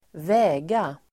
Uttal: [²v'ä:ga]